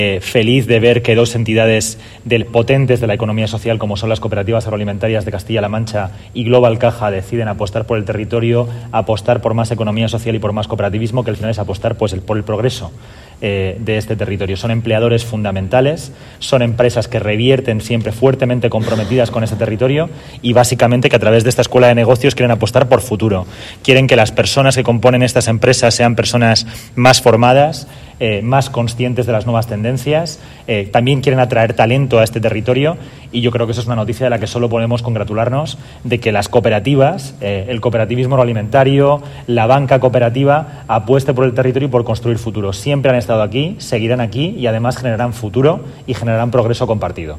Víctor Meseguer-Comisionado Especial de Economía Social